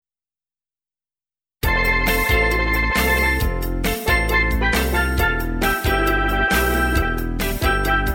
Steel Pan(128K)